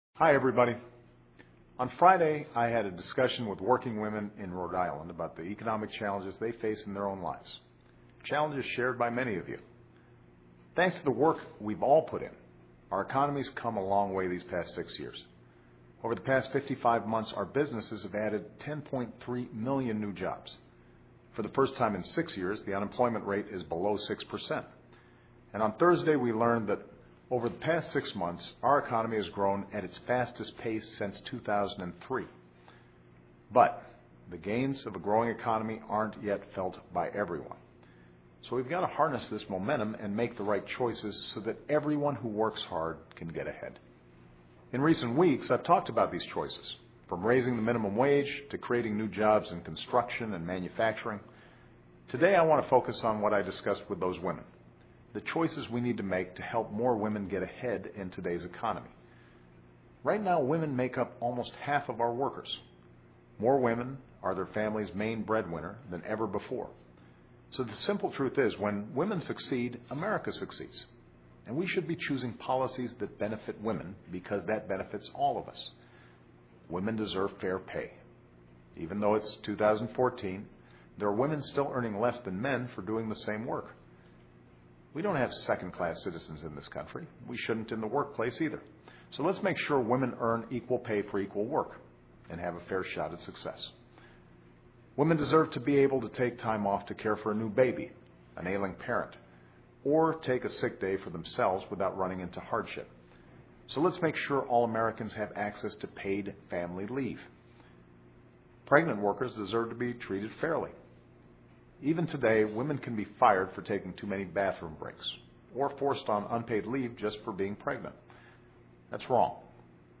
奥巴马每周电视讲话：总统呼吁帮助妇女和工薪家庭 听力文件下载—在线英语听力室